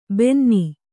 ♪ bennī